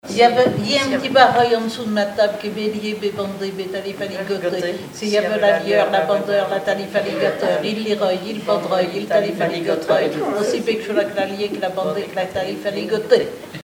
formulette enfantine : amusette
Pièce musicale inédite